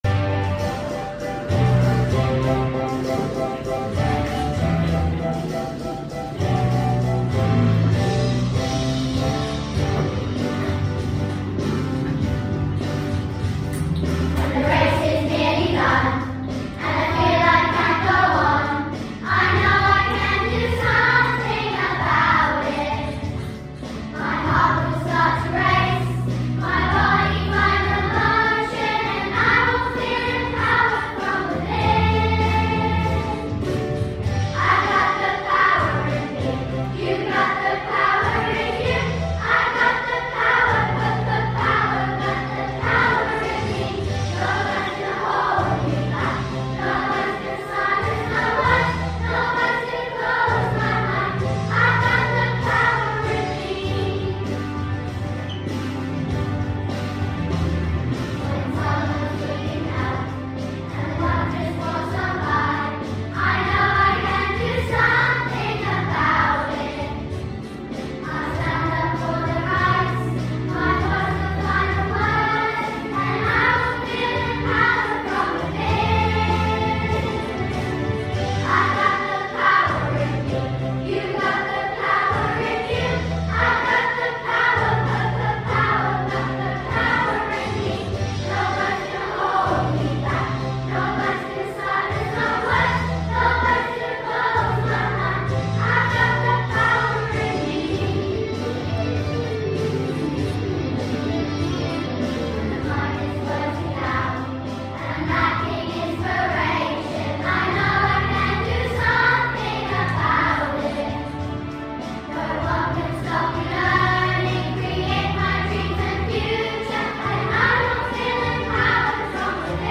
Power in Me | Larch Choir